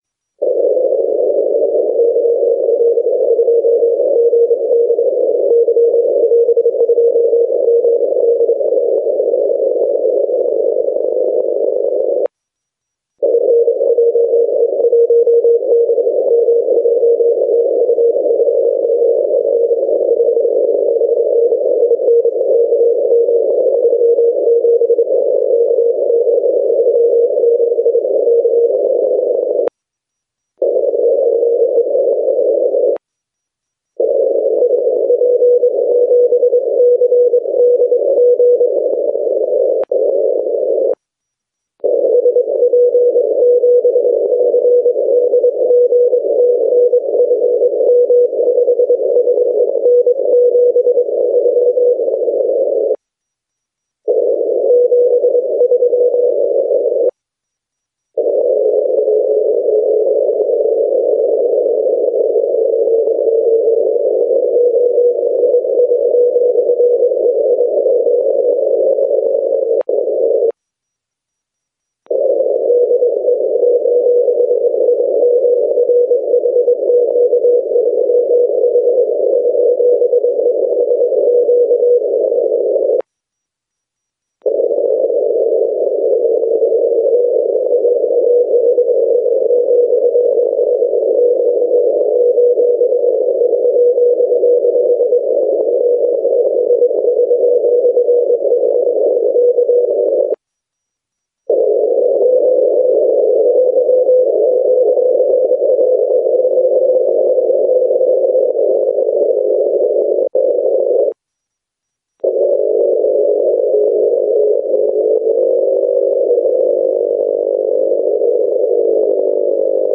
SNR have getting worse by the buzz (video signal) of the CCTV - China Central Television station on 49.75 MHz.